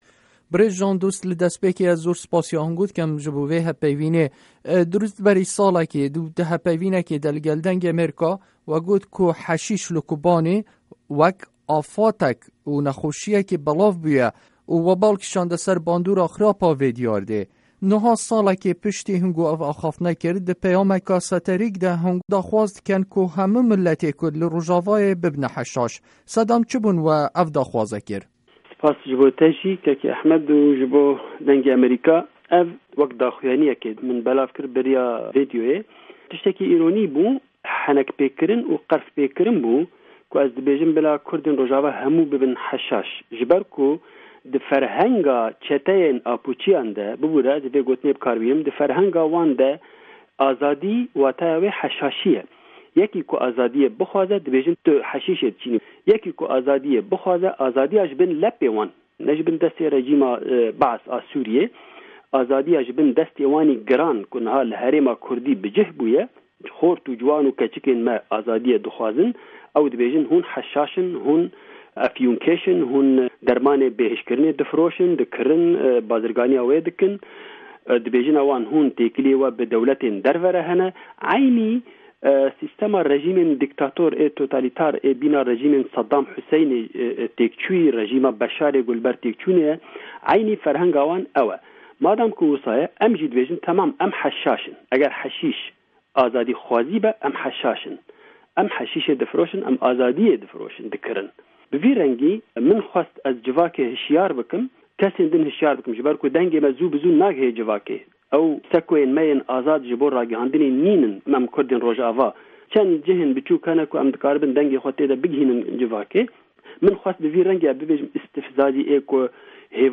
Hevpeyvîn bi birêz Jan Dost re